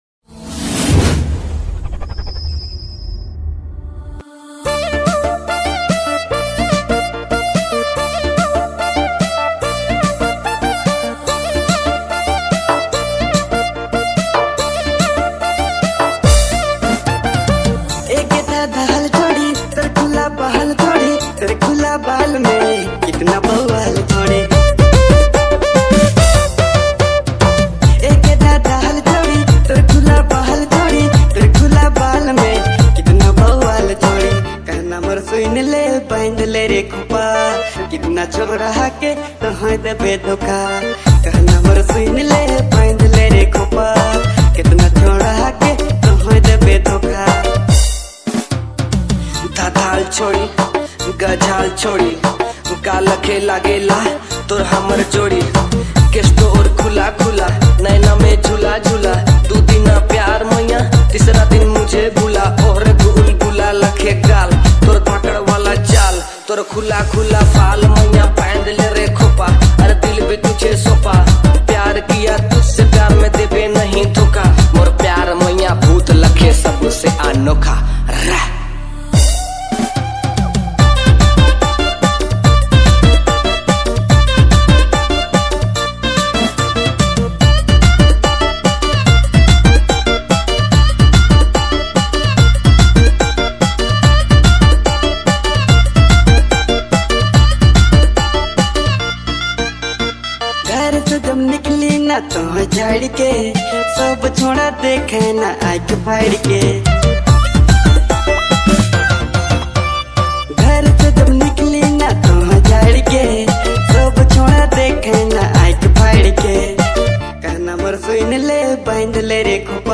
New Nagpuri All Singer Songs